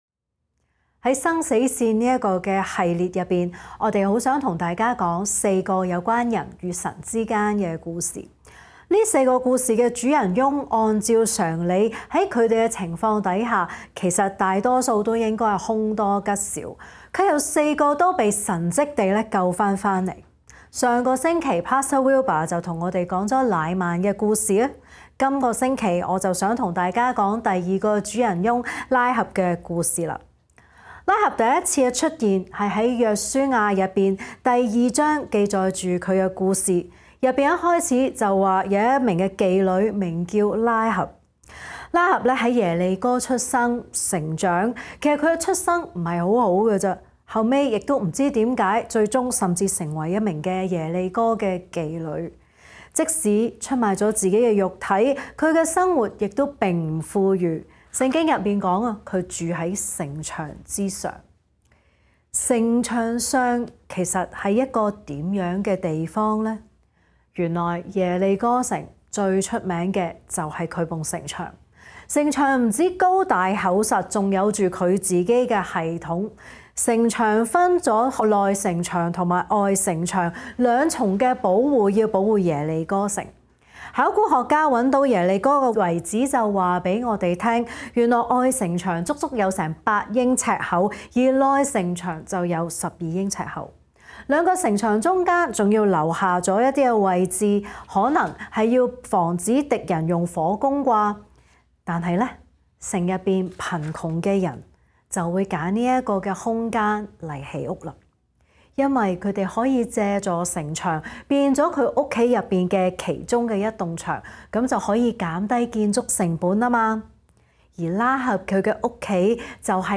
講道搜尋 | Koinonia Evangelical Church | 歌鄰基督教會